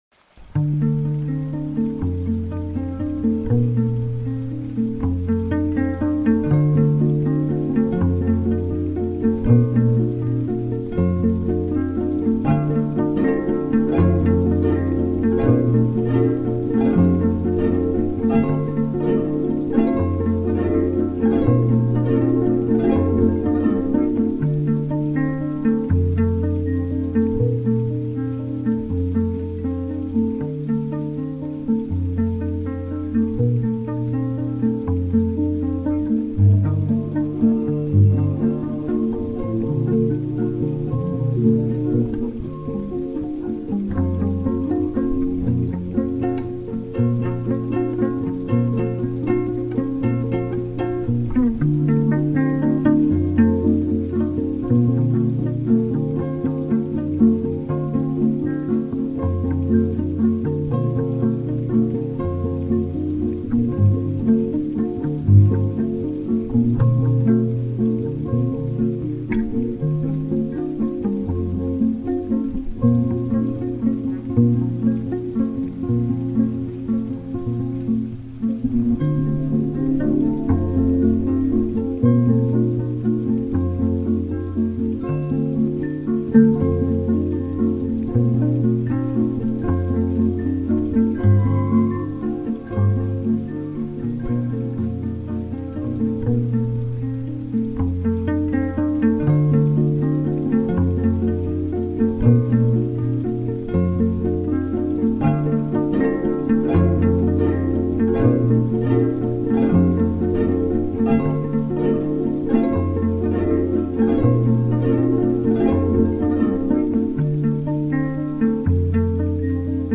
epic instrumental music